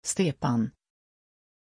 Pronunciation of Stéphanie
pronunciation-stéphanie-sv.mp3